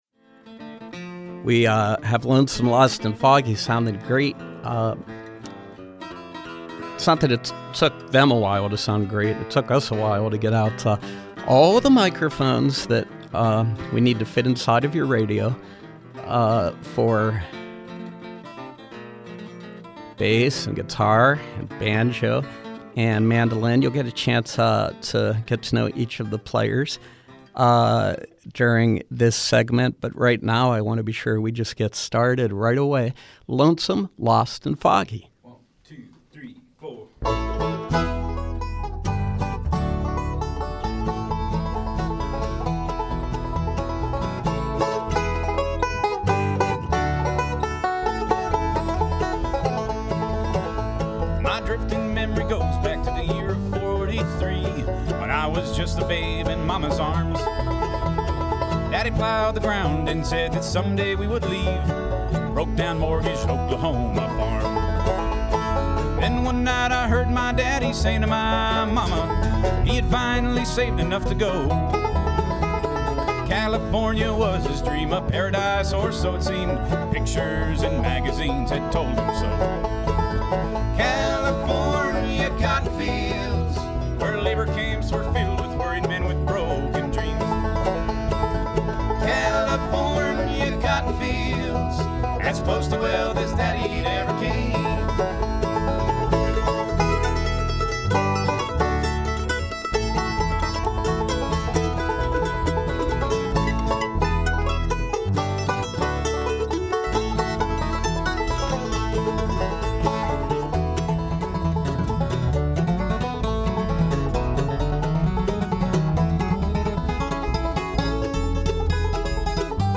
Live bluegrass music